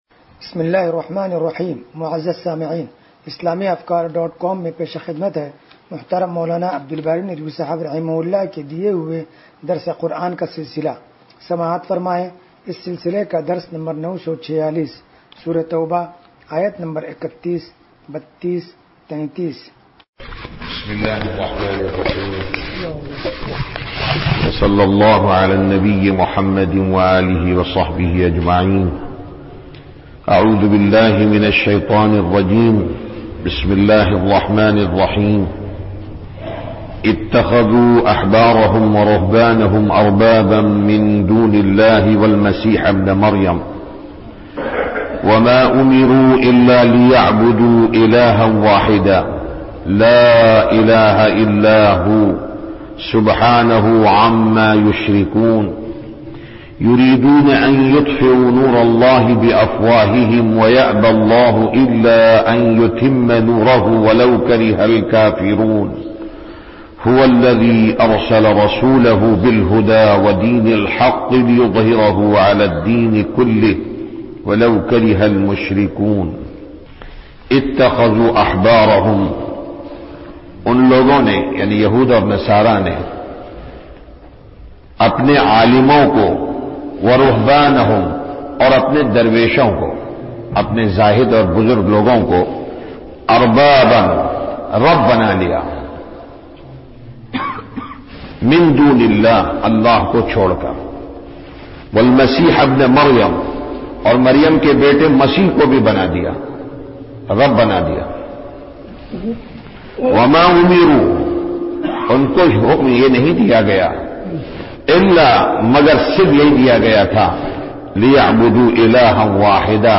درس قرآن نمبر 0946
درس-قرآن-نمبر-0946.mp3